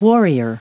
Prononciation du mot warrior en anglais (fichier audio)